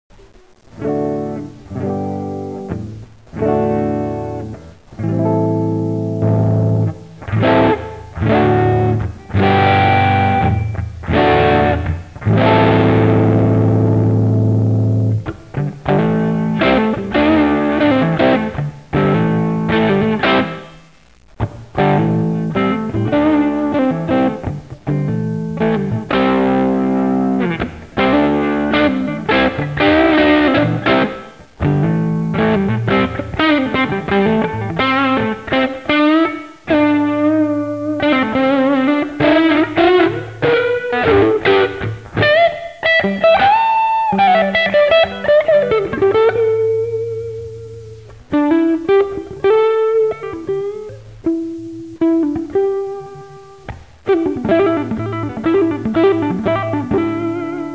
Note 3: In the heat my S470 developed a buzz, so there is some buzz in the HB clips - it's my guitar/playing not the VC
Setup1: Ibanez S470 (w/Gibson Classic '57/'57+ pickups) -> VAMP (for small combo)->computer sound card
Clip 2: Over-Drive Neck HB: VC set to Dr:70%, Tr:70%, B:60%, Vol:50% :I played using the neck HB pickup w/out the VC on for a D-G-D-am chord set then added it -
I play one lick, turn the vol back to 80% on the guitar, play just about the same lick, then turn it back up. The rest of the clip I try to show how the VC cleans up with pick attack when used as an overdrive
vc_5_dirt_HB_N.mp3